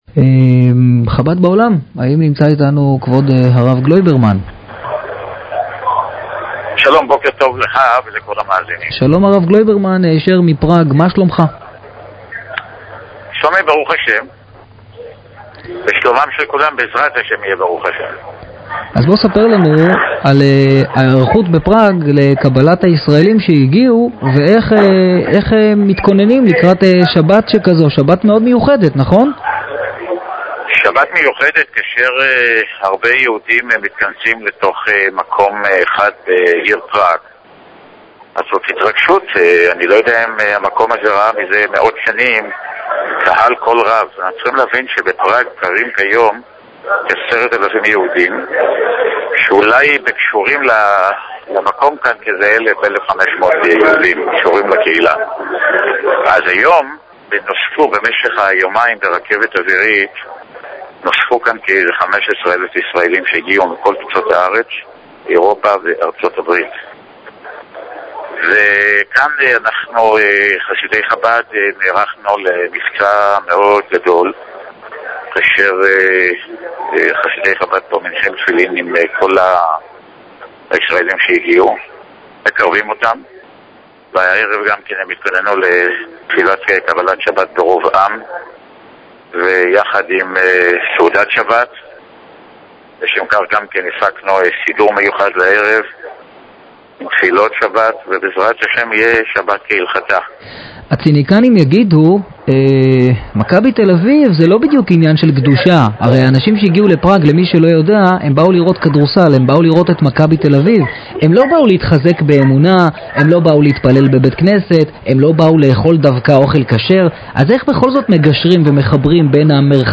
ראיון